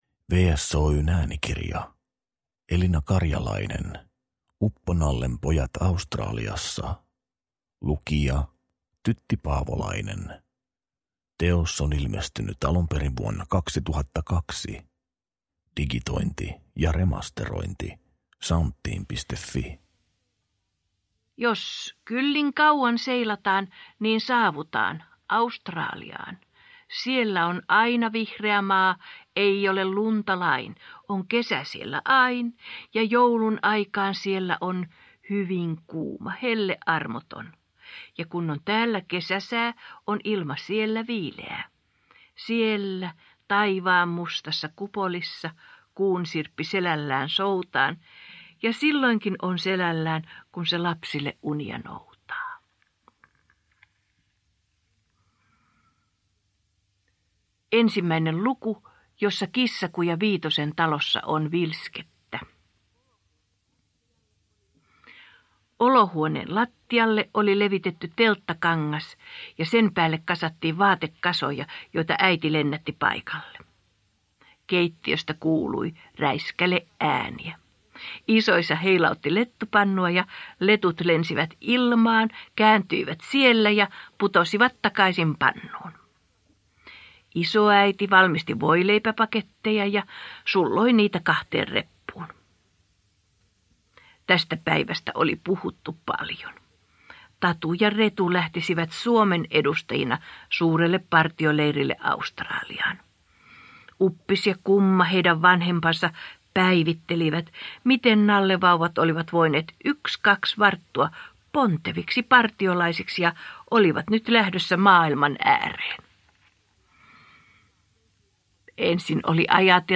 Uppo-Nallen pojat Australiassa – Ljudbok – Laddas ner